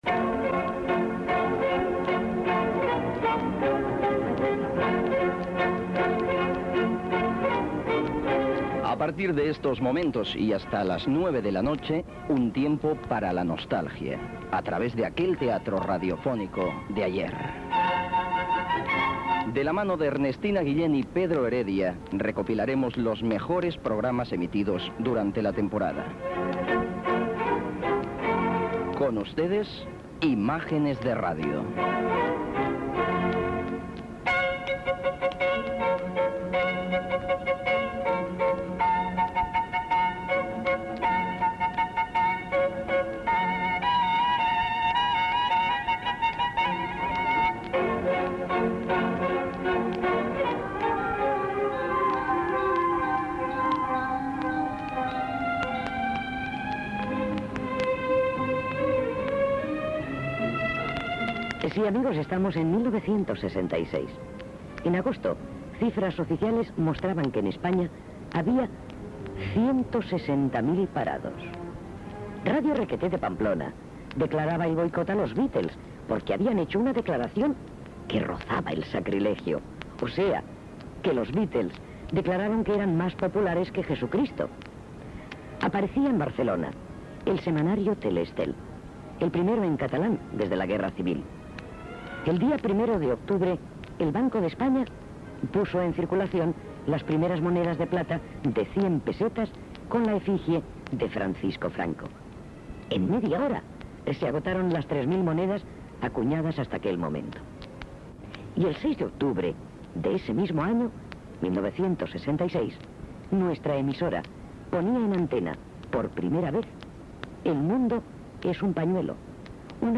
Careta del programa (veu Albert Castillón ) presentació del programa, dades i fets de l'any 1966, obra de radioteatre "El mundo es un pañuelo", amb els crèdits inicials
Ficció